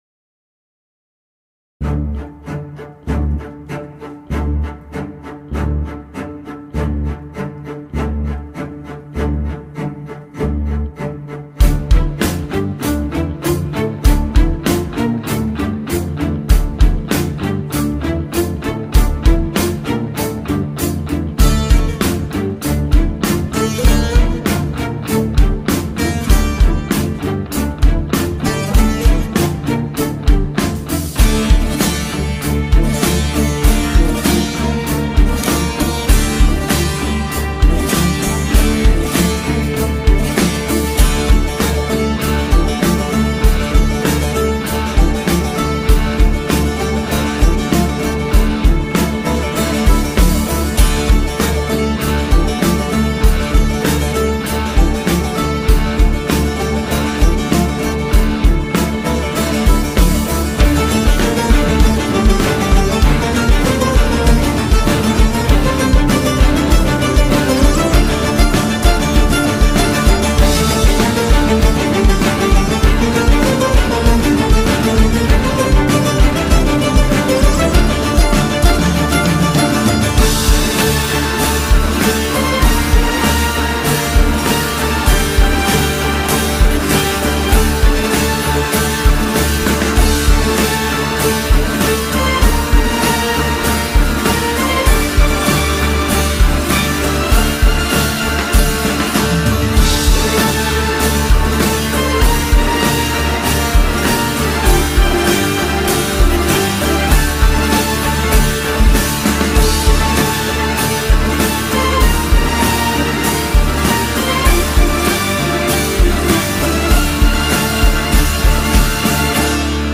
tema dizi müziği, duygusal heyecan aksiyon fon müzik.